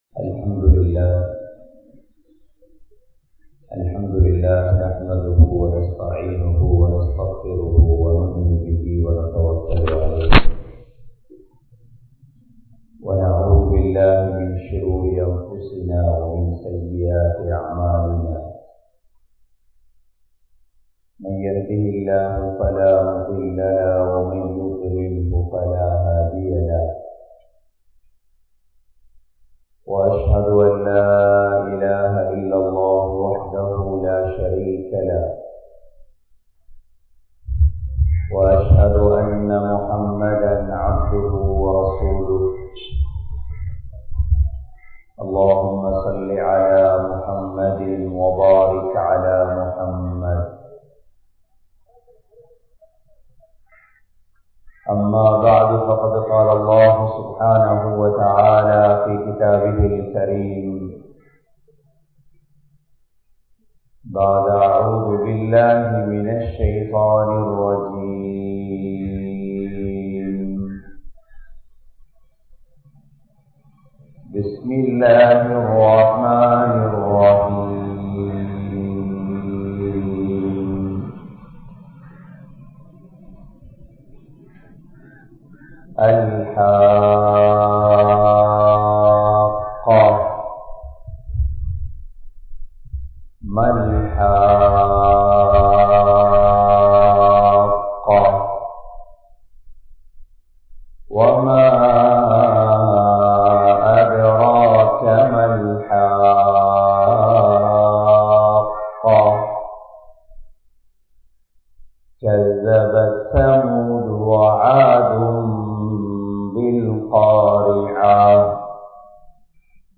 Surah Al Haqqah | Audio Bayans | All Ceylon Muslim Youth Community | Addalaichenai